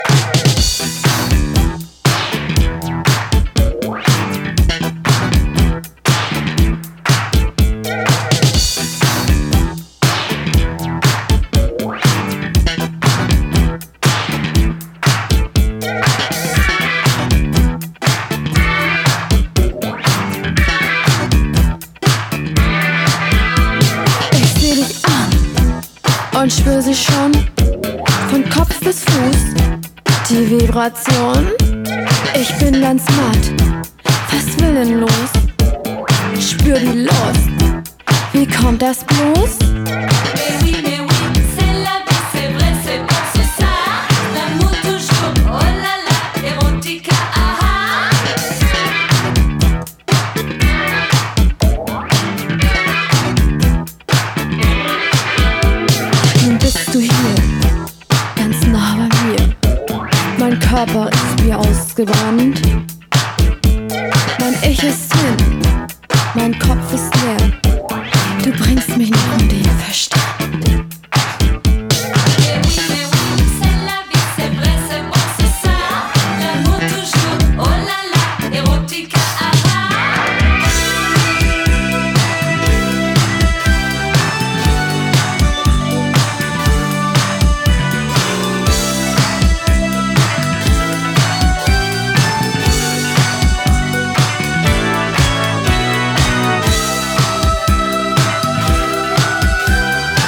ツボを抑えたエクステンドの前者、原曲を引き立てながらトリッピーな仕掛けを施した後者、いずれもナイス！